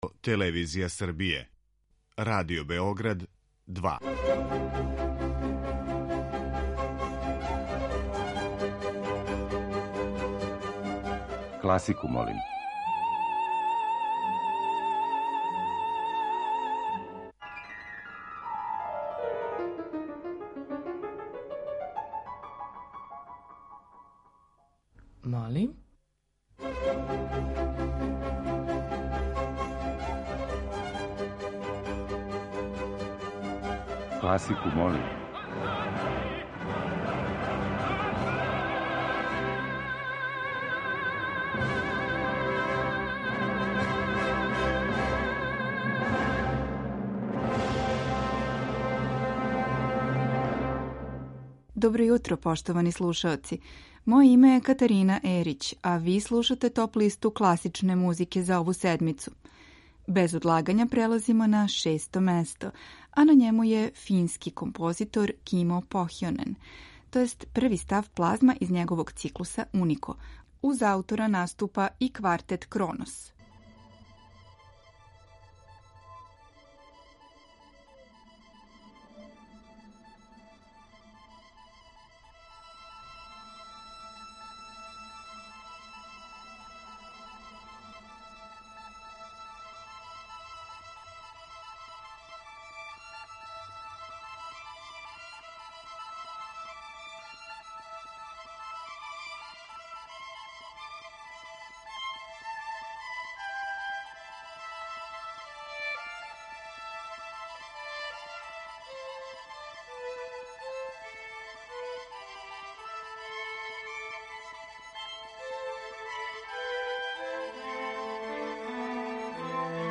Снимци најбољих такмичара на последњем Шопеновом конкурсу у Варшави.